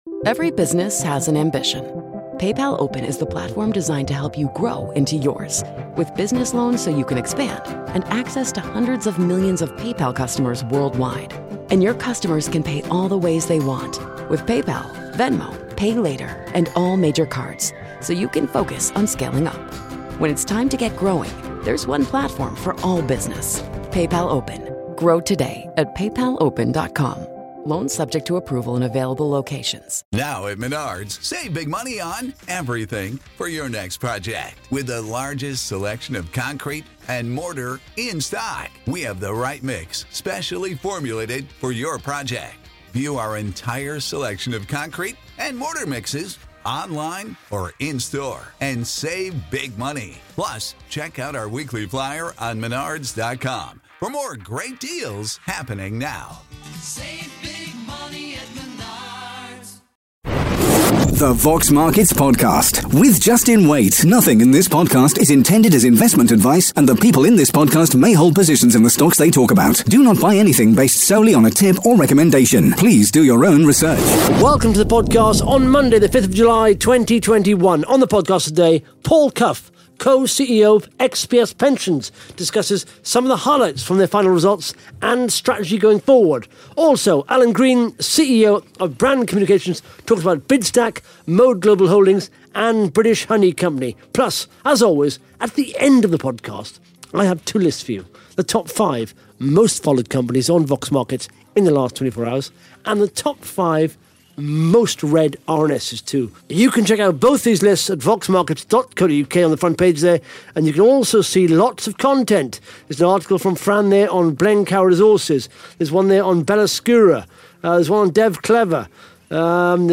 (Interview starts at 15 minutes 5 seconds)